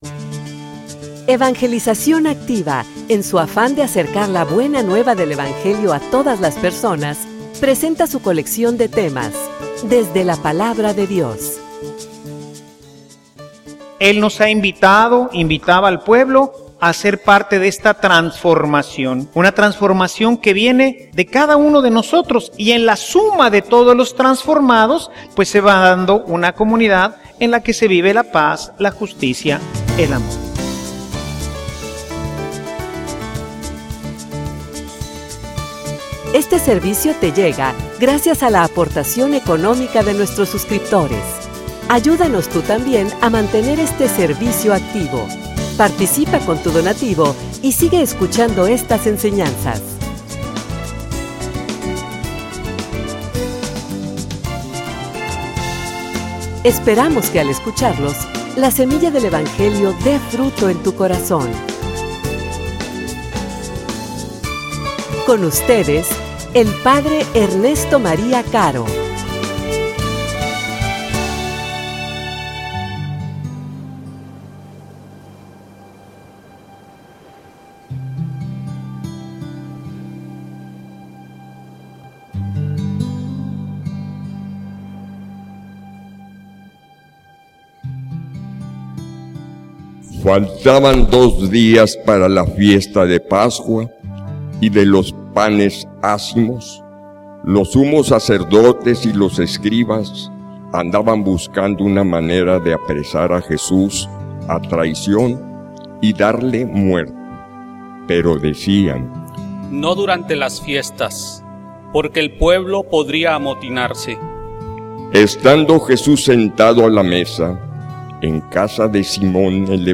homilia_No_olvides_jamas_que_Dios_te_ama.mp3